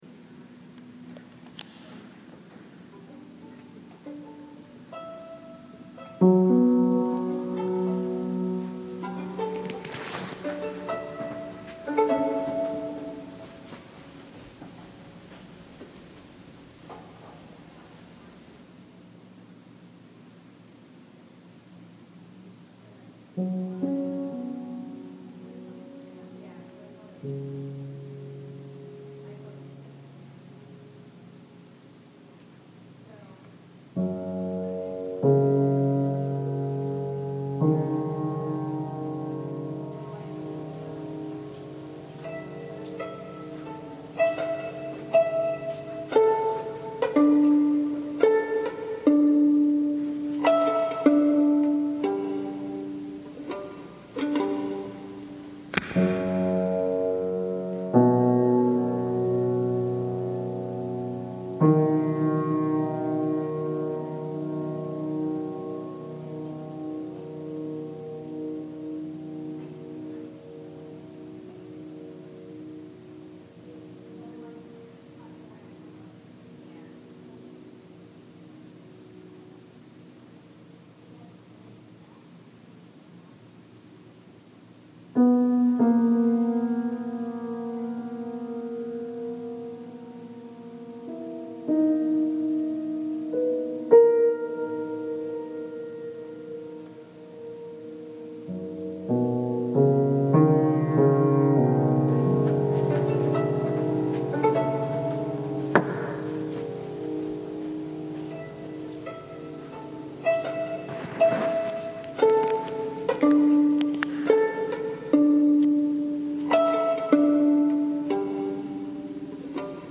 Industrial
Violin